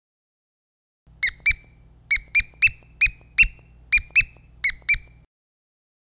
Squeaker Pillow Single-Voice - Medium (12 Pack) - Trick
Single-voice squeakers make a sound only when they are pressed, not when they are released.